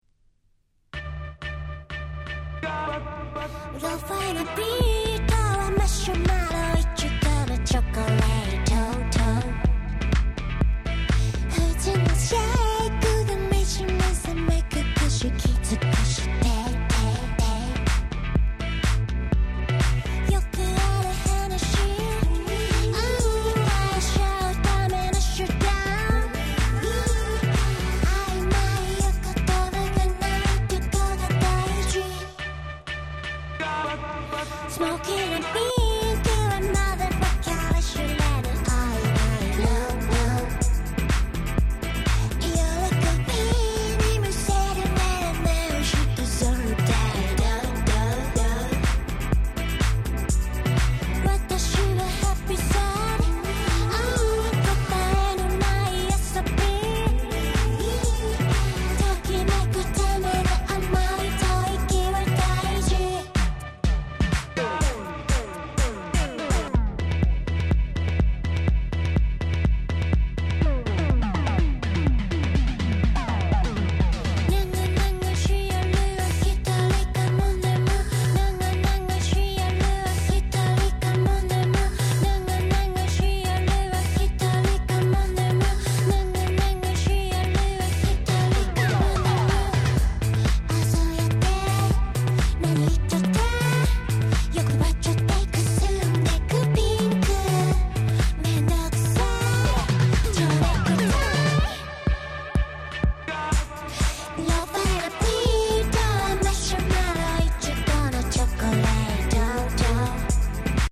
20' Super Nice J-Pop !!